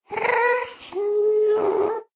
minecraft / sounds / mob / ghast / moan6.ogg
moan6.ogg